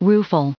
2031_rueful.ogg